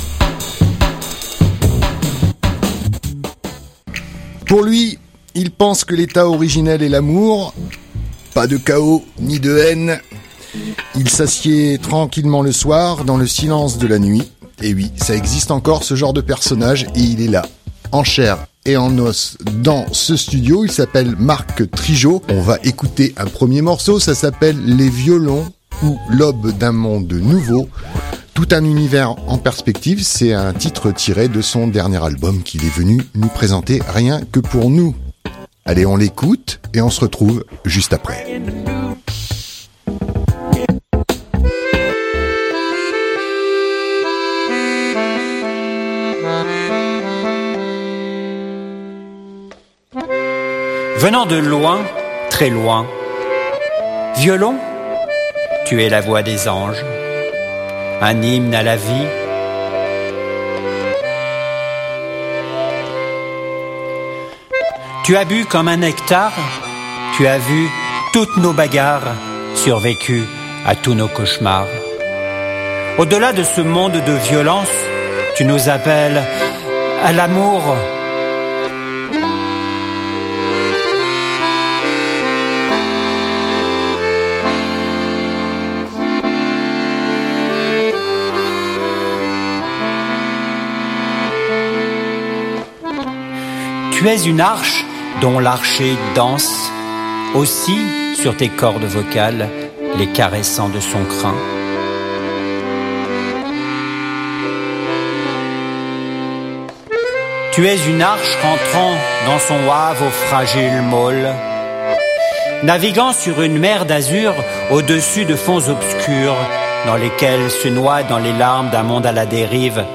Le poète nous ouvre son regard sur le monde dans cette entrevue, poussière de grâce, quelques instants où la joie de se découvrir l'emporte sur la vanité et la laideur de ce monde. Celle-ci est brièvement évoquée et fait aussitôt place à la présentation d'un artiste aux multiples facettes qui vient nous offrir un album riche, pudique, intense et drôle, conçu comme l'est une marionnette qui prend vie quand on l'écoute vraiment.